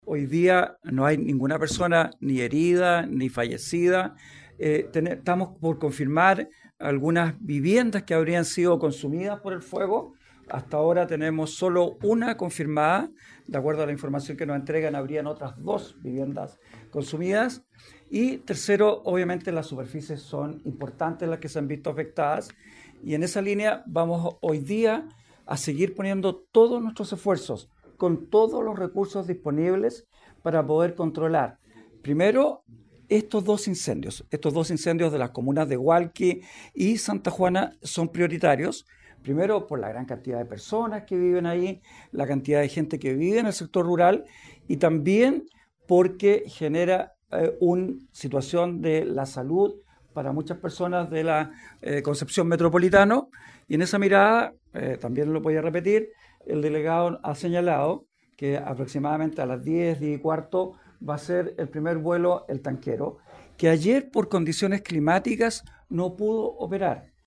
Por su parte, el director regional de Conaf, Esteban Krause, enfatizó en que las prioridades son: resguardar la vida de las personas, la infraestructura y vivienda y, por último, los recursos naturales, tarea que se “ha cumplido”. Además, anunció que desde hoy va a operar un avión tanquero para aumentar el combate a los incendios desde el aire.